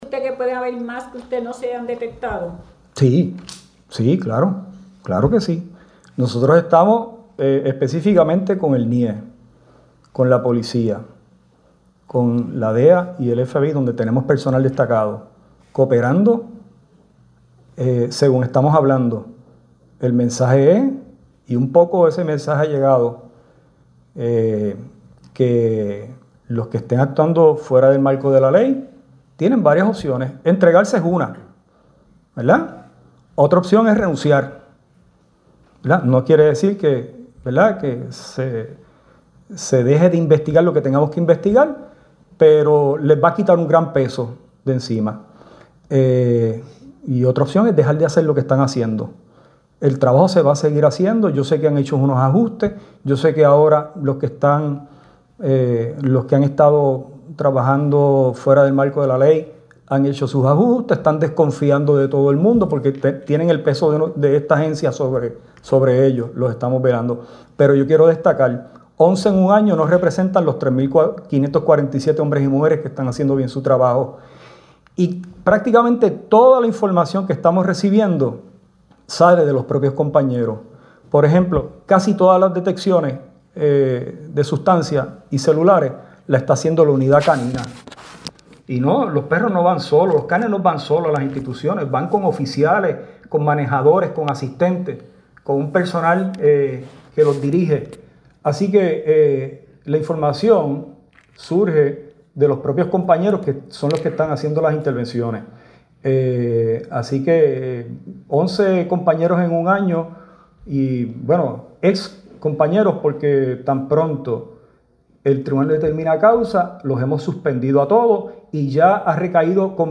(3 de marzo de 2026)-El secretario del  Departamento de Corrección y Rehabilitación (DCR) de Puerto Rico, Francisco Quiñones  recalcó hoy que ha implementado medidas severas ante la corrupción, incluyendo el uso de polígrafos, pruebas de dopaje, reinvestigaciones de personal y la suspensión inmediata de oficiales sospechosos de introducir contrabando, a pregunta de Foronoticioso, si él entendía que todavía pueden  quedar guardias de seguridad que a pesar de las medidas que ha tomado para evitar la corrupción.